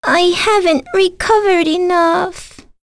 Lewsia_A-Vox_Dead.wav